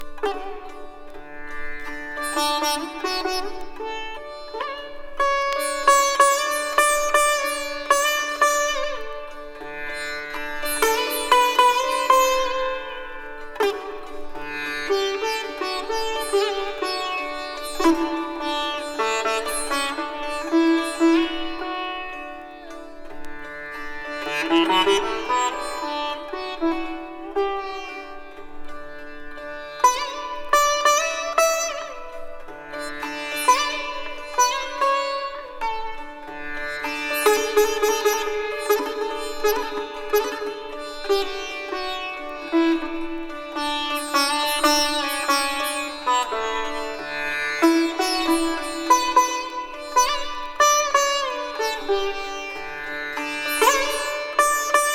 シタール、タブラと、ギター、ベース、ドラムの最高峰天界セッション。'75年ドイツ録音。